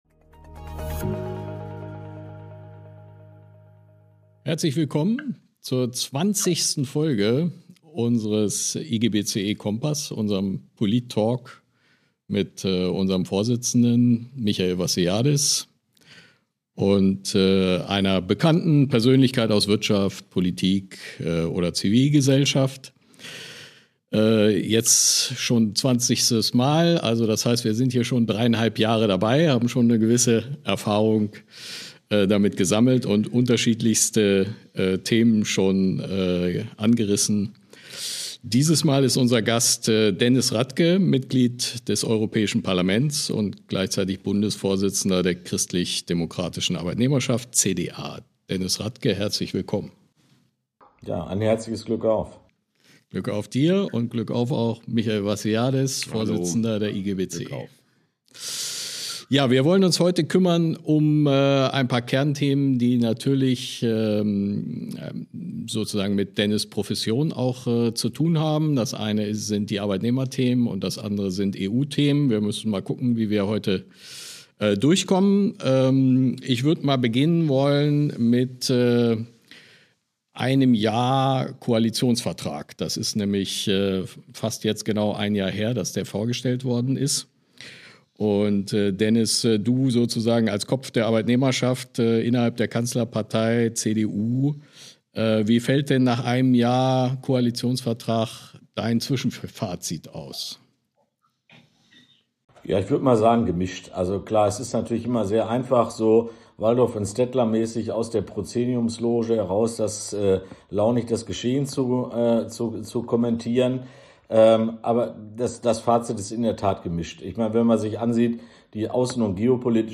Beschreibung vor 2 Wochen Lifestyle-Teilzeit, Work-Life-Balance, Krankfeiern: Die Aussagen von Bundeskanzler Friedrich Merz zur Arbeitsmoral der Deutschen haben bei vielen Beschäftigten für große Empörung gesorgt. Im aktuellen „Kompass-Talk“ diskutiert der IGBCE-Vorsitzende Michael Vassiliadis mit Denis Radtke, dem Bundesvorsitzenden der Christlich-Demokratischen Arbeitnehmerschaft (CDA) und Mitglied des Europäischen Parlaments, über die Arbeitszeitdebatte, Sozialpolitik und europäische Maßnahmen, um die heimische Industrie zu schützen.